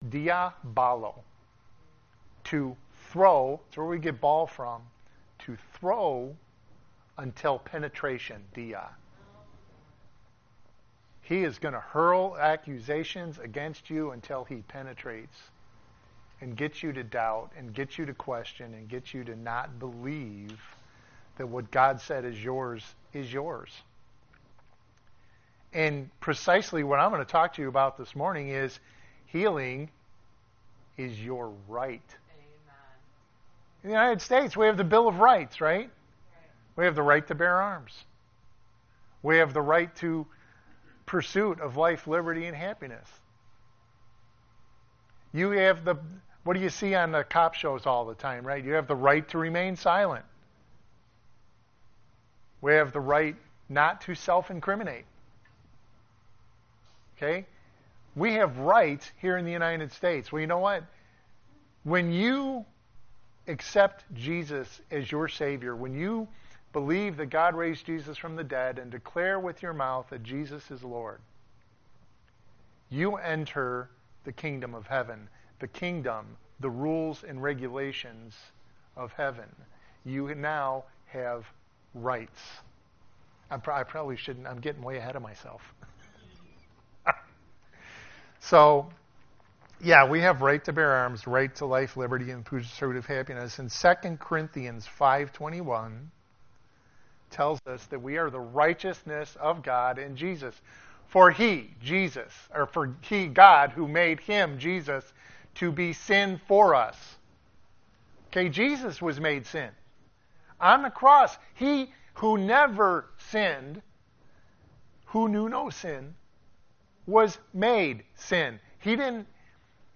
Special Guest Speaker May 19 2024 Service Type: Sunday Morning Service « Part 2